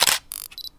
cam_snap.mp3